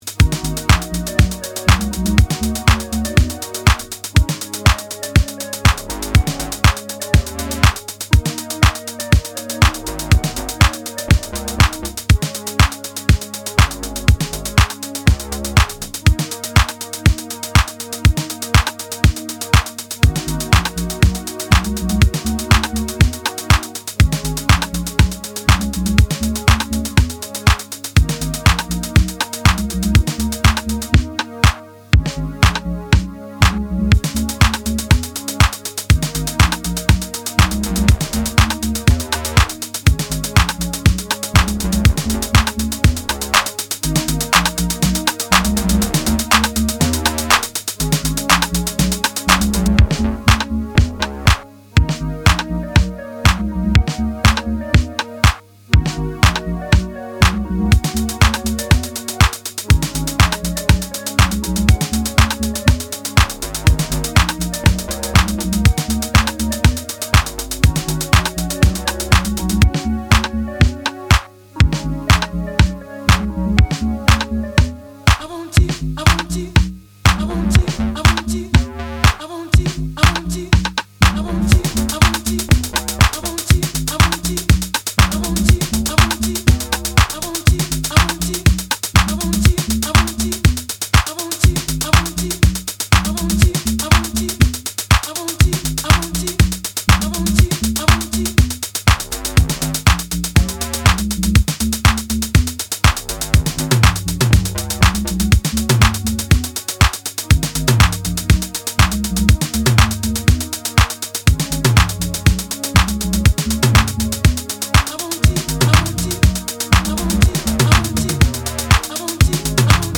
House Acid